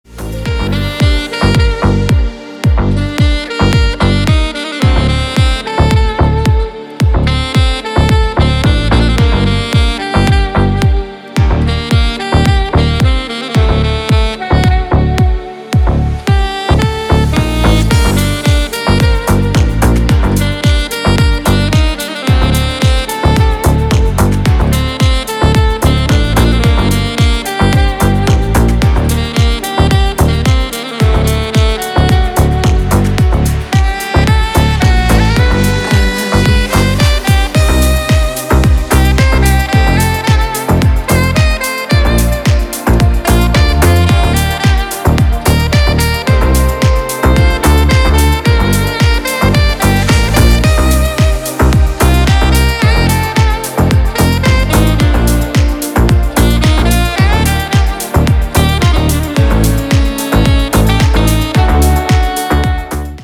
саксофон на звонок